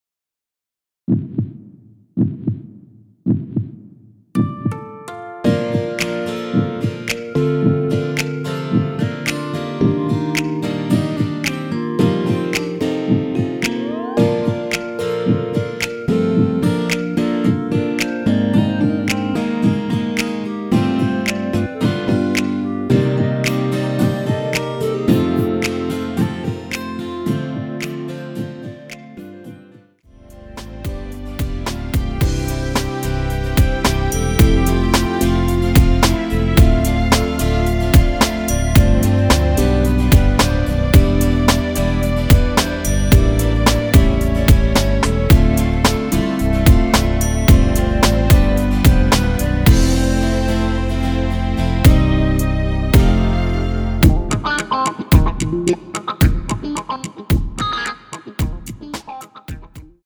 원키에서(-3)내린 멜로디 포함된 MR입니다.(미리듣기 확인)
Bb
앞부분30초, 뒷부분30초씩 편집해서 올려 드리고 있습니다.
(멜로디 MR)은 가이드 멜로디가 포함된 MR 입니다.